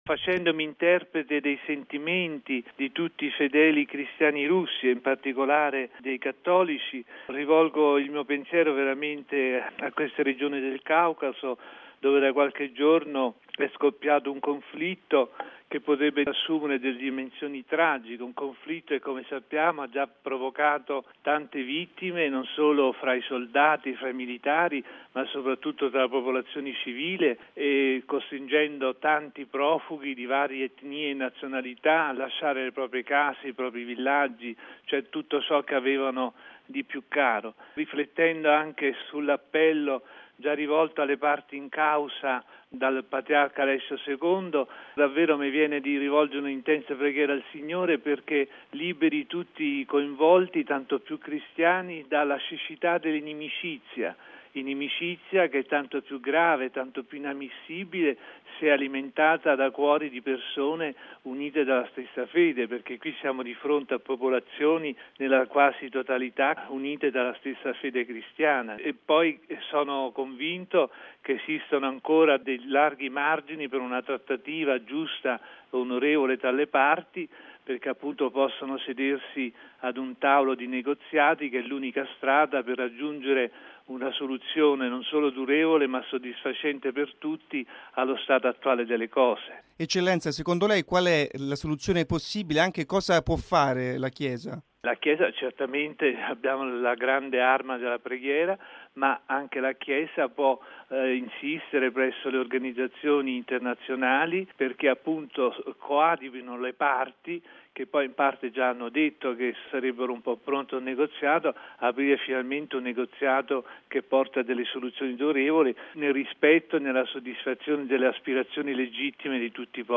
Sulla crisi tra Russia e Georgia interviene anche il nunzio apostolico Antonio Mennini, rappresentante della Santa Sede nella Federazione Russa. Il presule definisce il conflitto "una inutile strage" e invoca il ritorno al dialogo. L'intervista